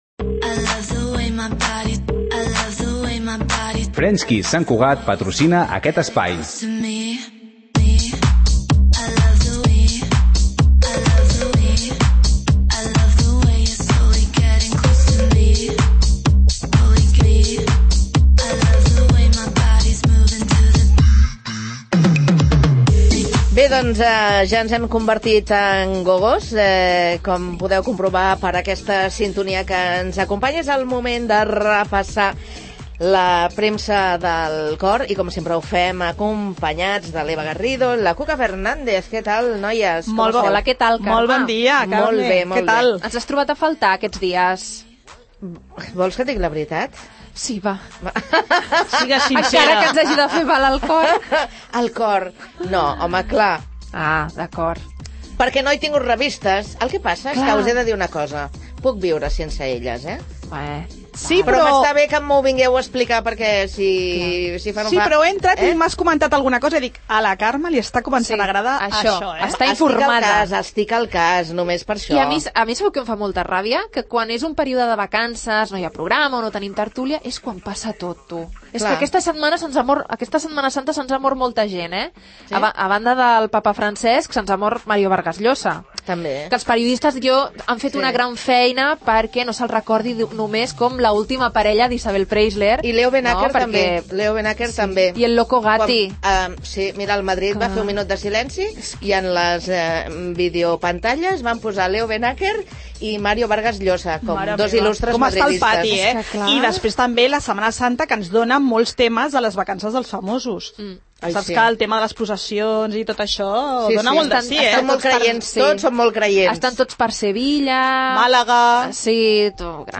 Han trencat Gerard Piqu� i Clara Ch�a? Els detalls, a la tert�lia del cor de R�dio Sant Cugat